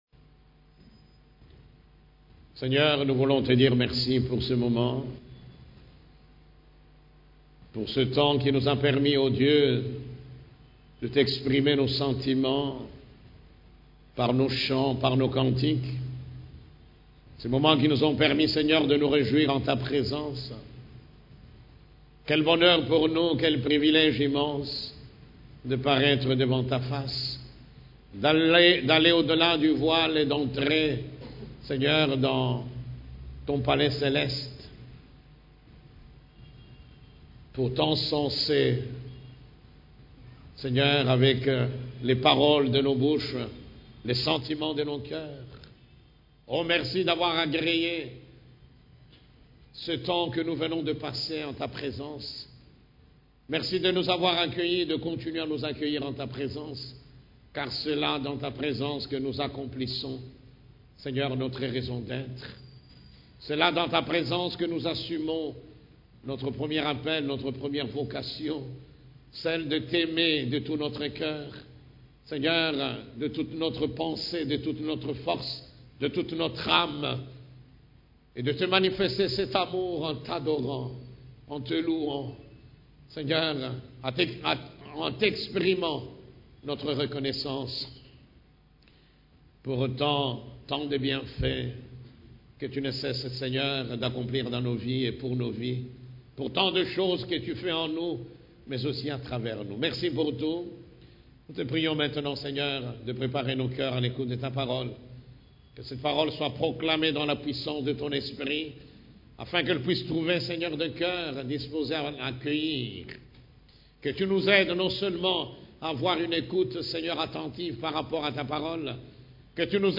CEF la Borne, Culte du Dimanche, L'ordre divin dans le relationnel